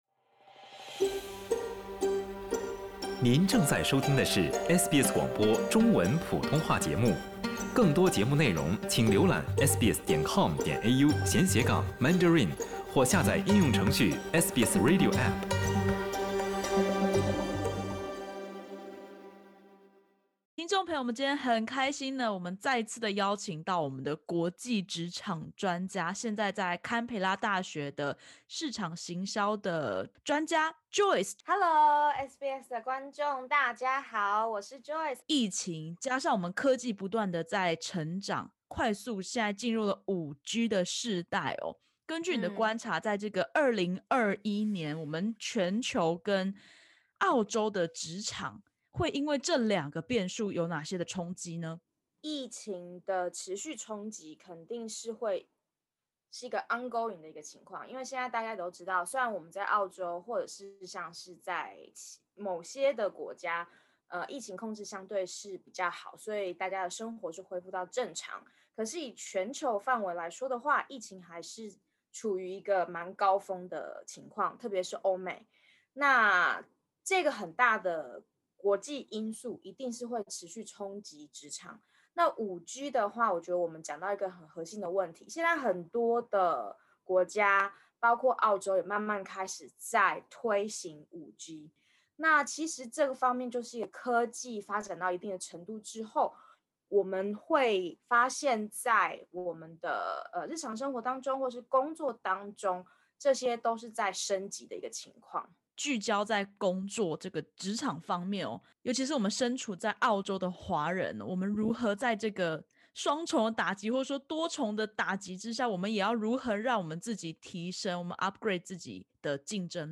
疫情冲击加上5G世代来临，加强远距工作力和科技力，将成为您的职场超能力。点击首图收听完整采访音频。